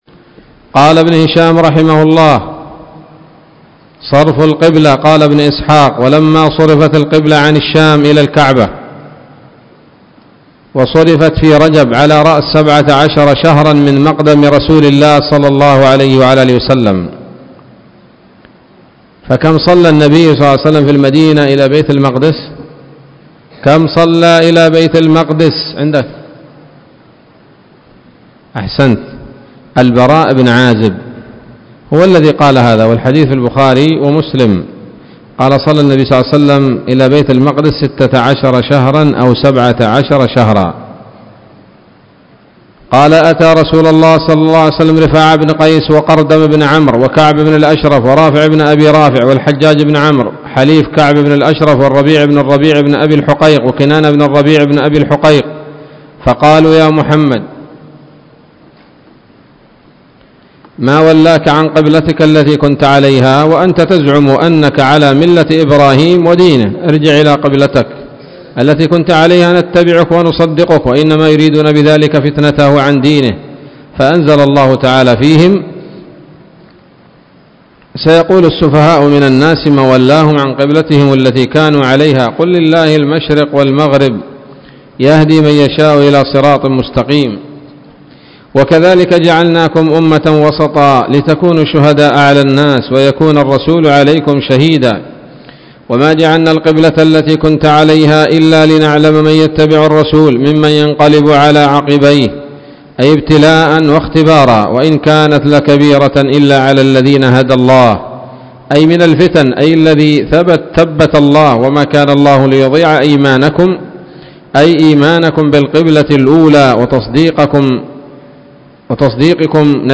الدرس الثالث والتسعون من التعليق على كتاب السيرة النبوية لابن هشام